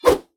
combat / weapons / default_swingable / fire2.ogg
fire2.ogg